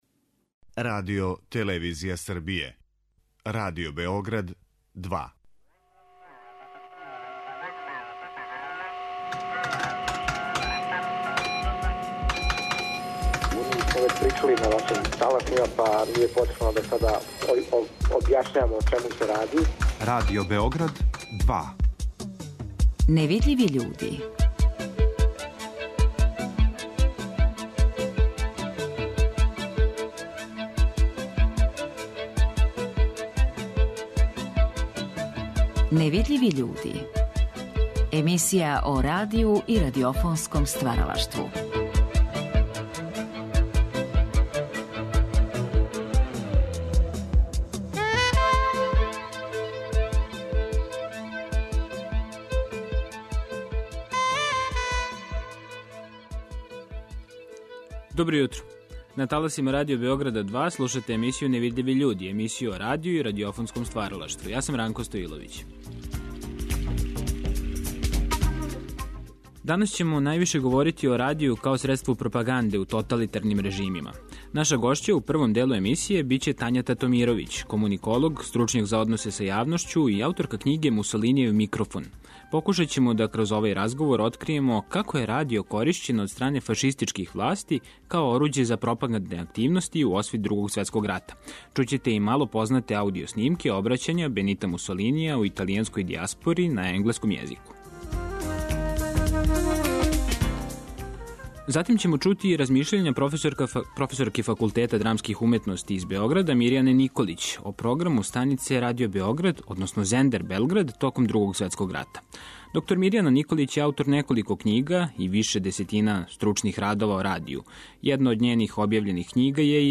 Сазнаћемо како је радио коришћен од стране италијанских власти и корпорацијских удружења као оруђе за пропагандне активности у освит Другог светског рата. Чућемо и сачуване аудио записе Мусолинијевог обраћања италијанској дијаспори на енглеском језику.